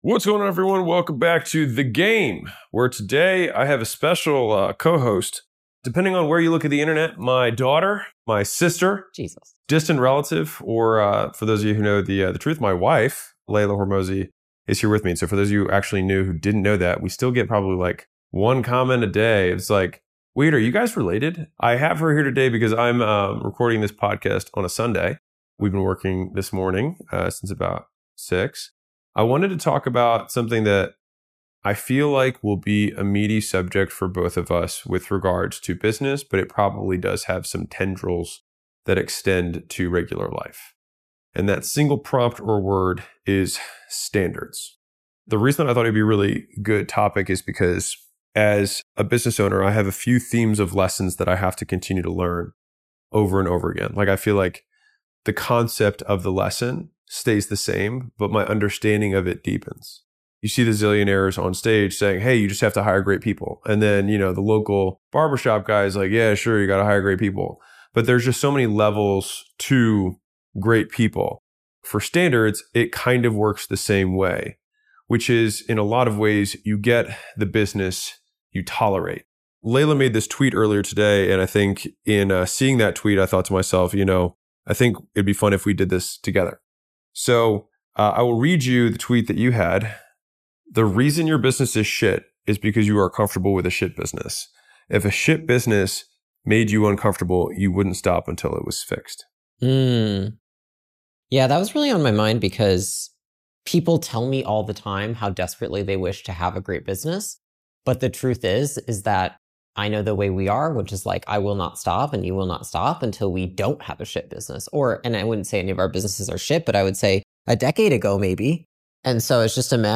Welcome to The Game w/Alex Hormozi, hosted by entrepreneur, founder, investor, author, public speaker, and content creator Alex Hormozi. On this podcast you’ll hear how to get more customers, make more profit per customer, how to keep them longer, and the many failures and lessons Alex has learned and will learn on his path from $100M to $1B in net worth.
SC-11-10-A-L-Convo-Alex-1.mp3